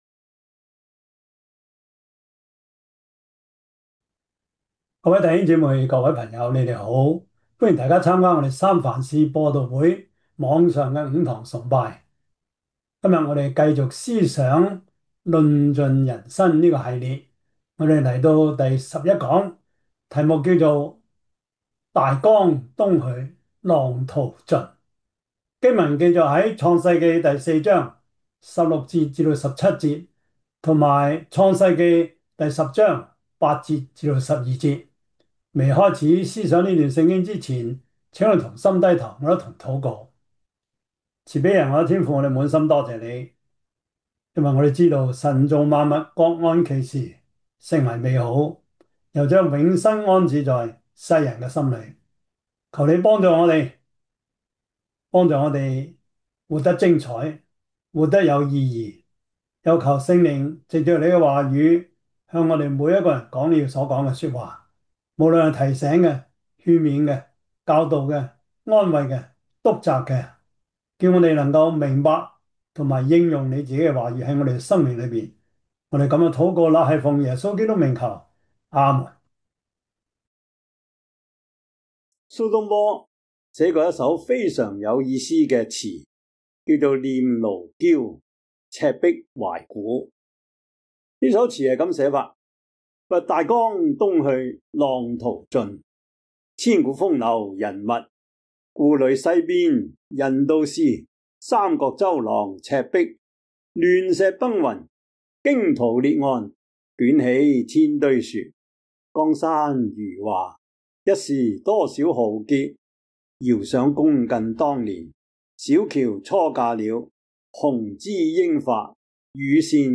10:8-12 Service Type: 主日崇拜 創世記 4:16-17 Chinese Union Version